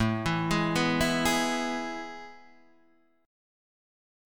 A Major 7th Suspended 2nd